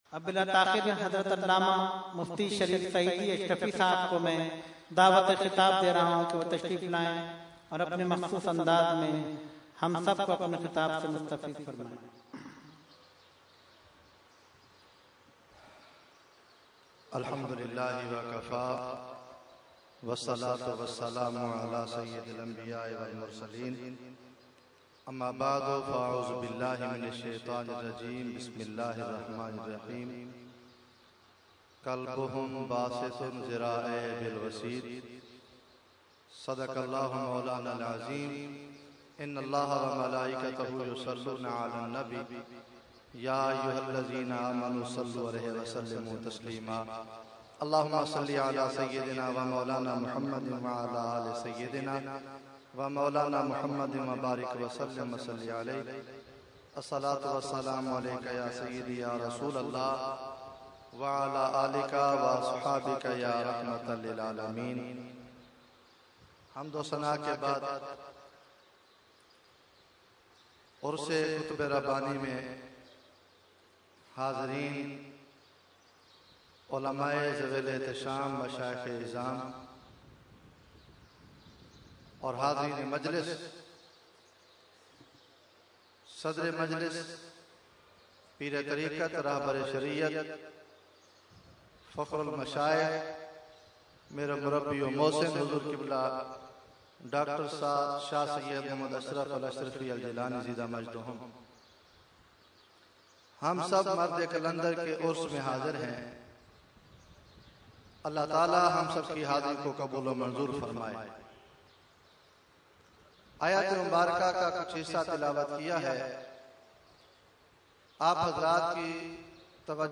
Category : Speech | Language : UrduEvent : Urs e Qutb e Rabbani 2013